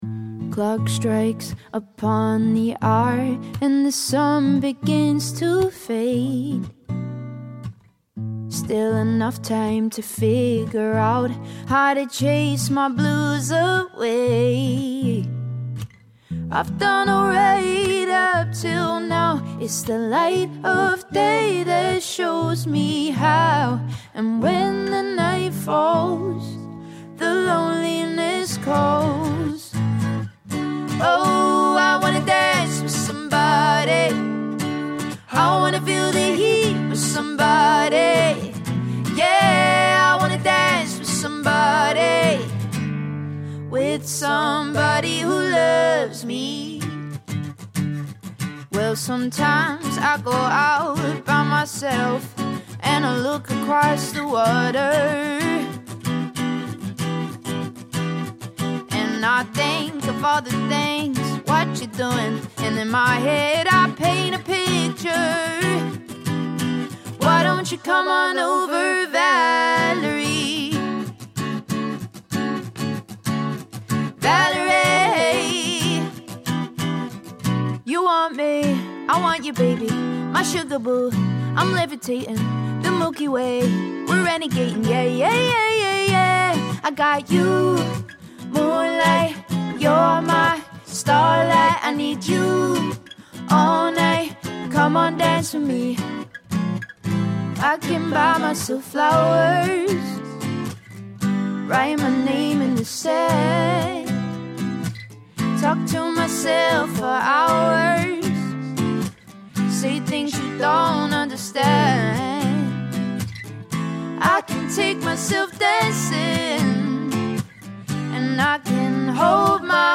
Female Singer, Male Singer/Guitarist